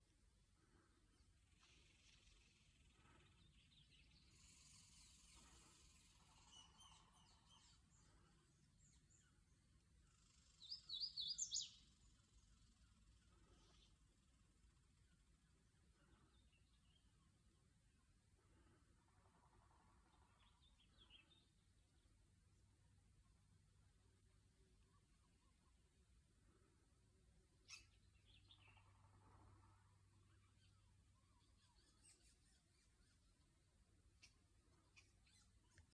Hooded Warbler Sight Record #2024-40